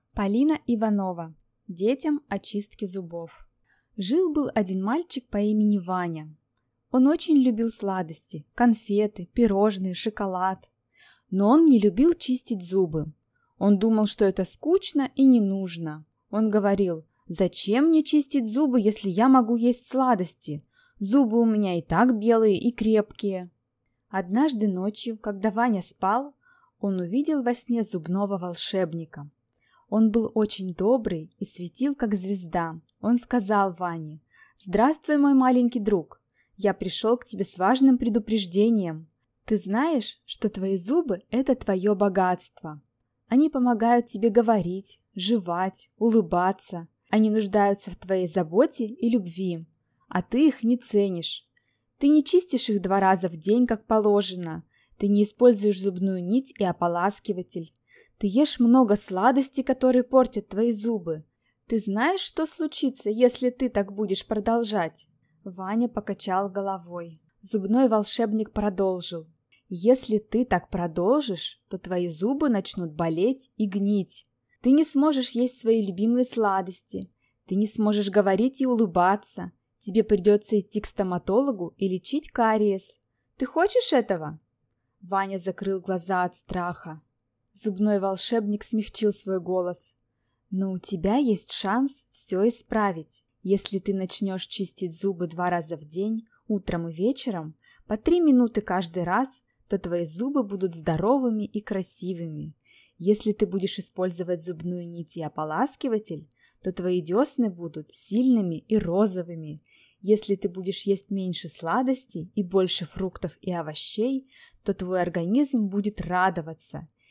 Аудиокнига Детям о чистке зубов | Библиотека аудиокниг